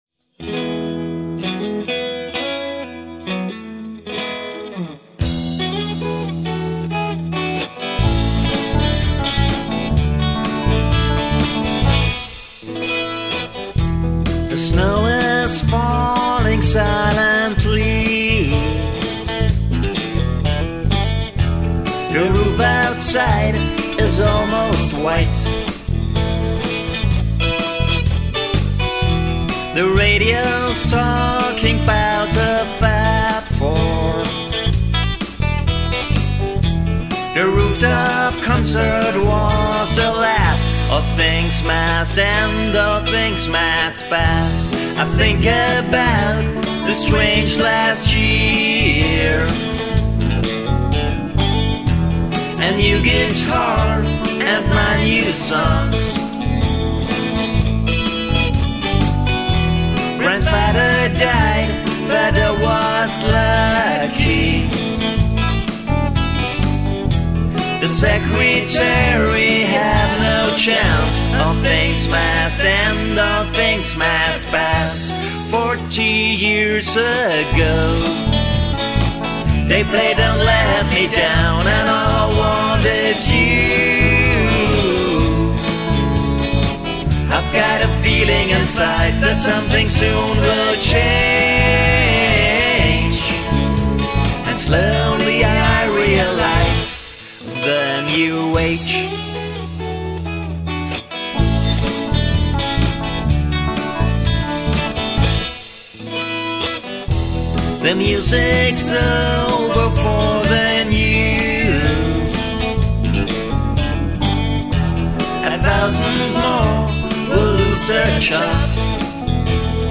vocals, electric guitars, bass, drum arrangement